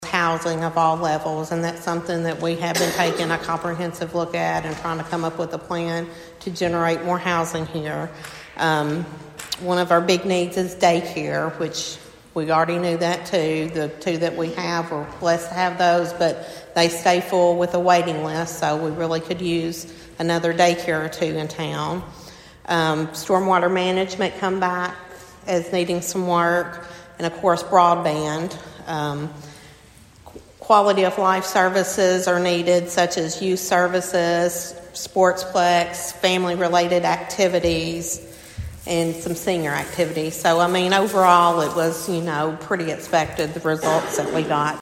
presented the results of the Comprehensive Plan Survey at Monday night’s City Council meeting.